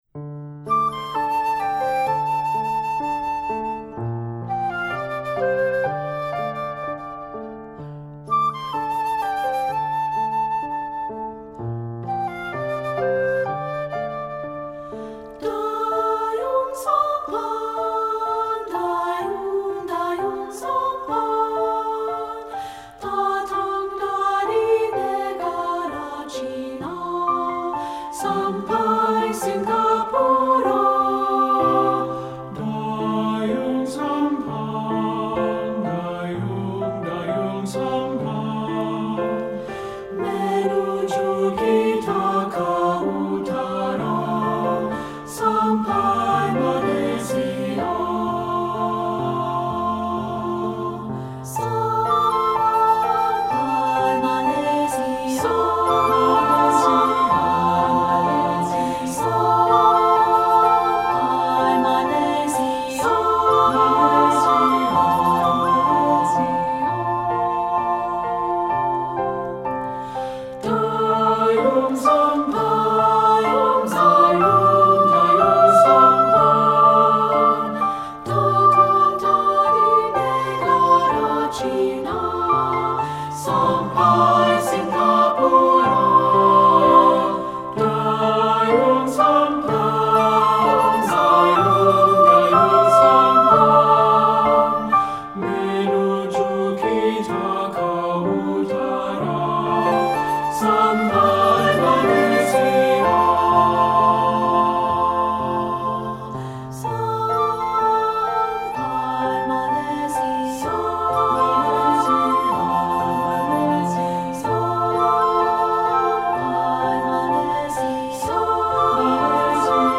Composer: Indonesian Folk Song
Voicing: Three-part